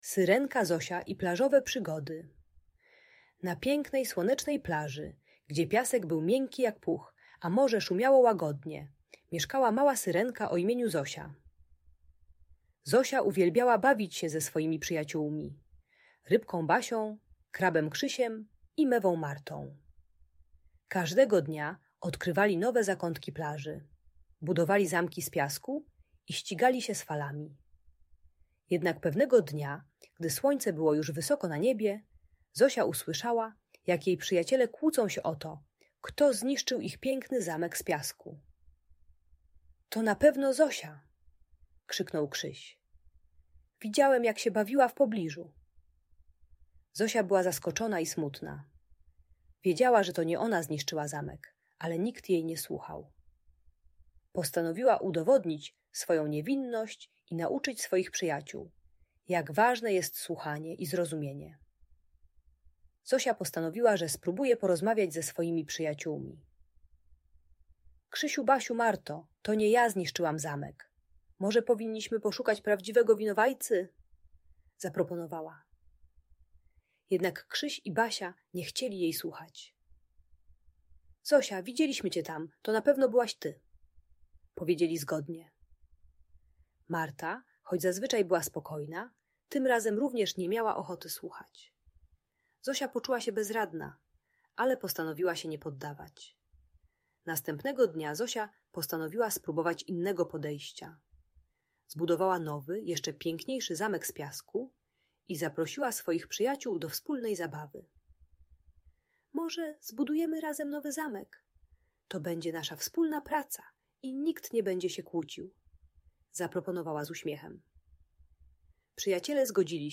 Historia o Przyjaźni i Odwadze - Audiobajka dla dzieci